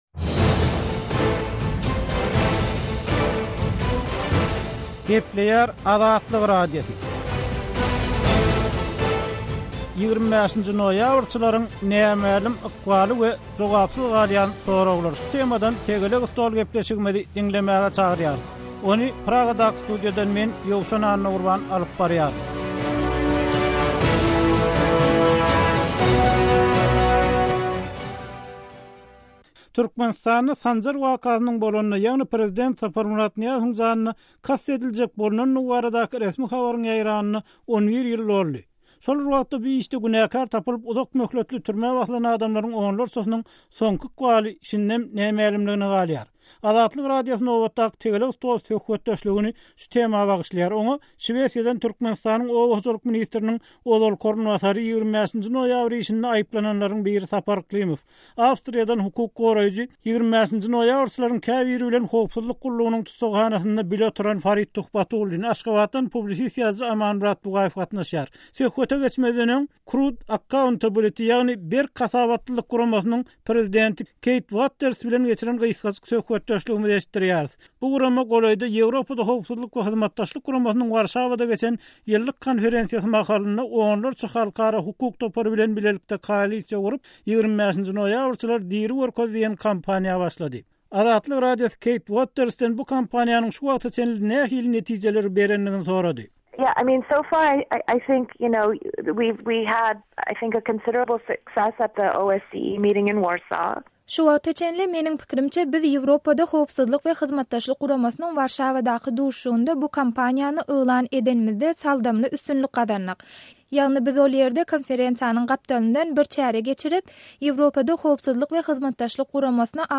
Azatlyk Radiosynyň nobatdaky «Tegelek stol» söhbetdeşligi şu meselä bagyşlandy.